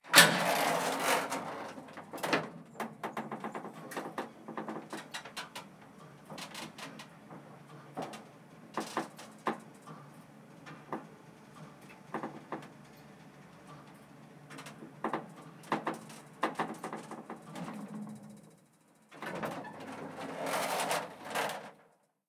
Ambiente interior de ascensor (motor)
Sonidos: Industria